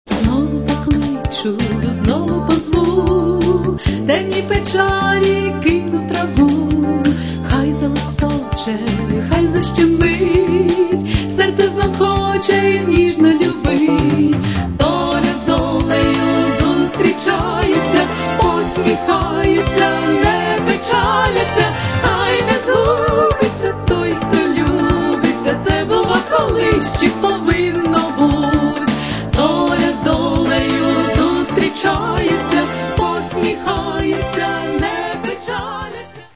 Каталог -> Естрада -> Дуети